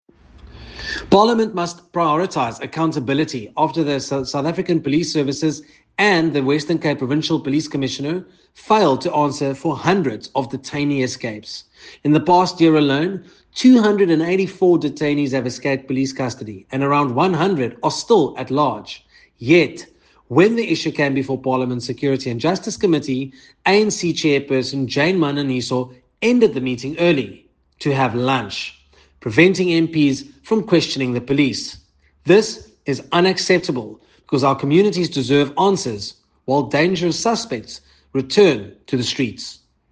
Issued by Nicholas Gotsell MP – DA NCOP Member on Security & Justice